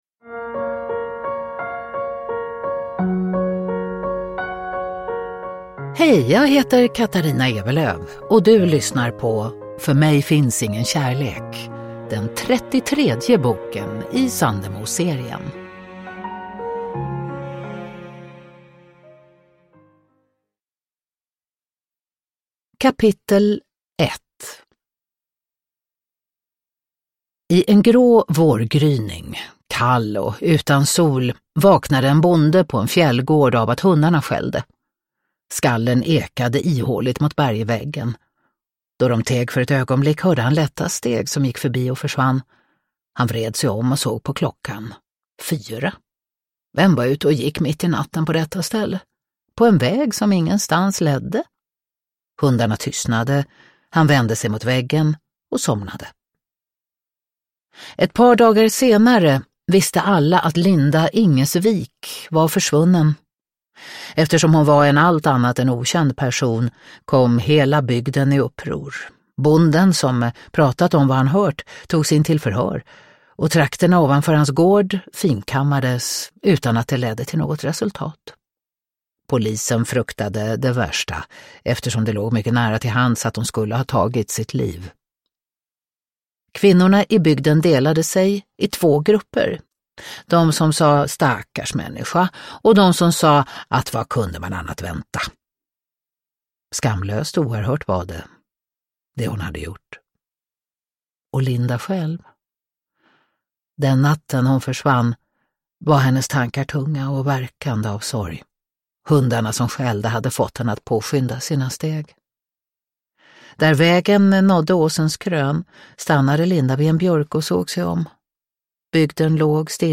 För mig finns ingen kärlek – Ljudbok – Laddas ner
Uppläsare: Katarina Ewerlöf